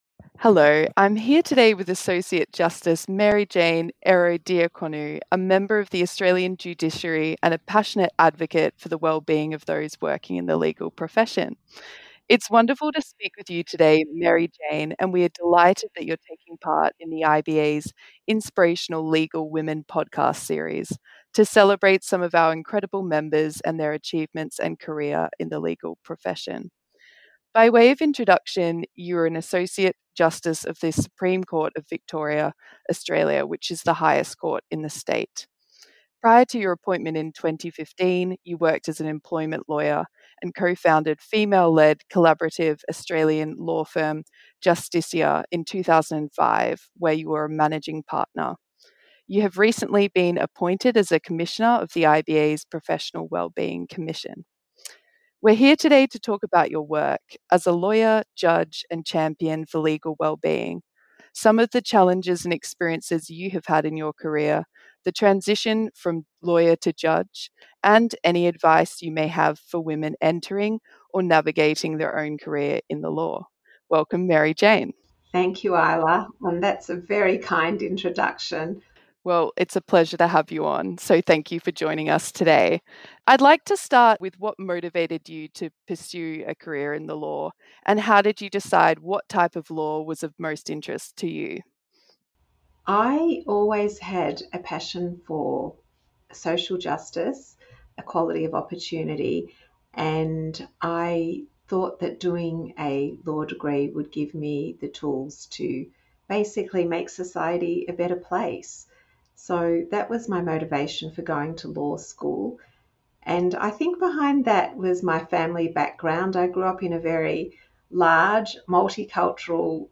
Inspirational legal women: a conversation with Associate Justice Mary-Jane Ierodiaconou